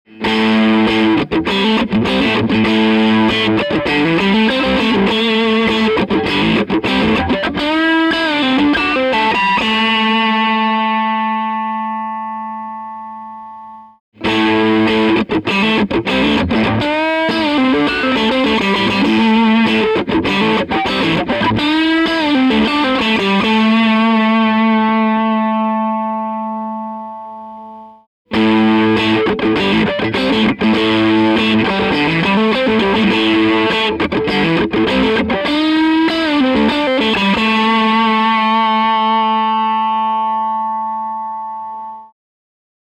Kytarové kabely
Použil jsem 3 typy kabelu v cenovém rozmezí od 0 - 1000,- Kč.
Test kabelů - 3x stejné nastavení aparátu i kytary (Fender Stratocaster orig. USA) ve formátu FLAC
kabely_srovnani.flac